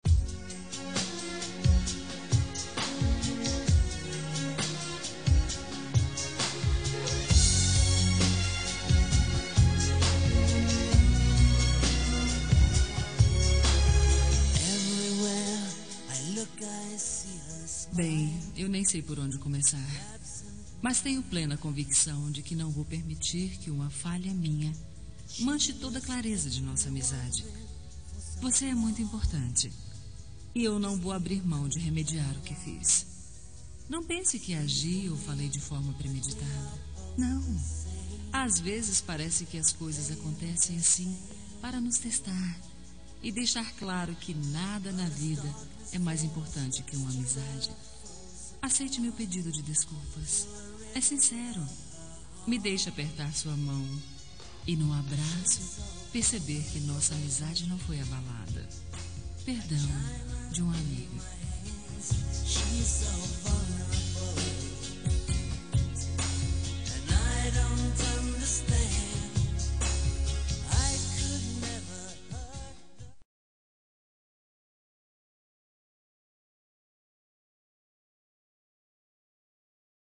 Reconciliação Amizade – Voz Feminina – Cód: 036791